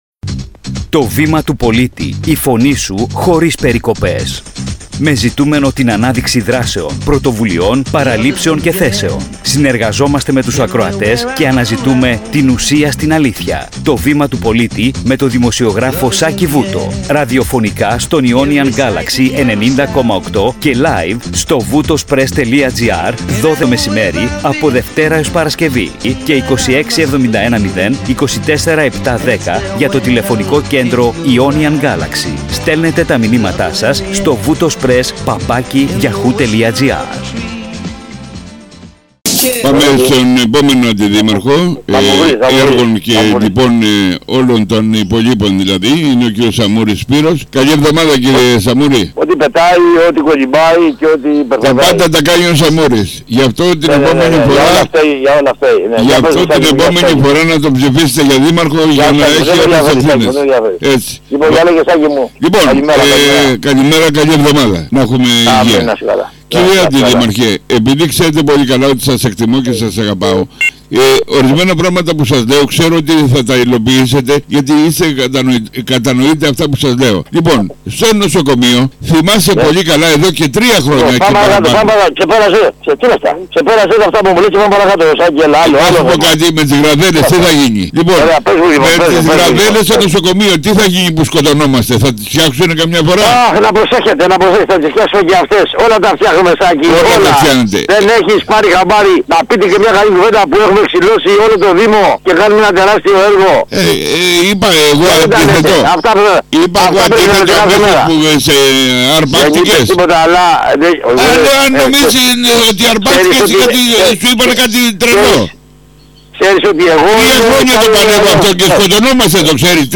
Η συζήτηση έχει τοπικό, καθημερινό χαρακτήρα, με αρκετή αυθορμησία και χιούμορ, αλλά και με έντονα σημεία για τα προβλήματα υποδομών (π.χ. δρόμοι, νοσοκομείο).
Ραδιοφωνική εκπομπή “Το Βήμα του Πολίτη”